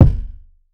Rattle Kick.wav